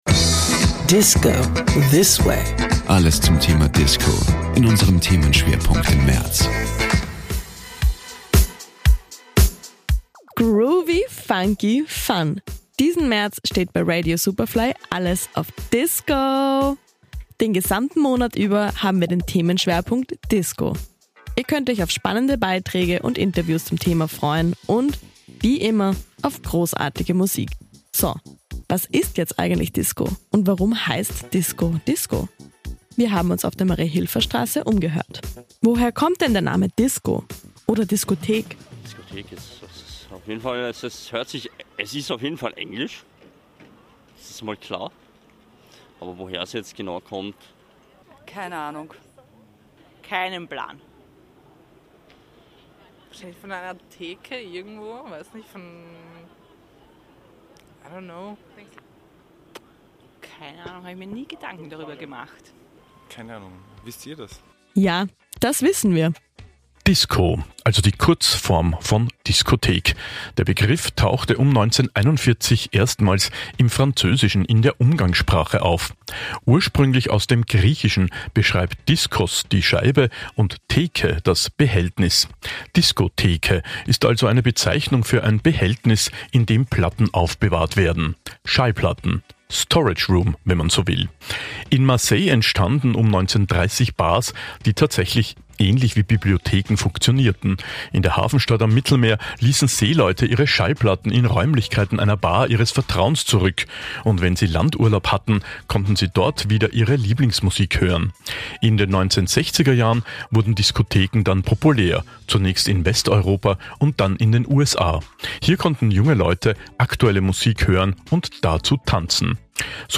Was ist jetzt eigentlich Disco und warum heißt Disco - Disco? Wir haben uns auf der Mariahilfer Straße umgehört...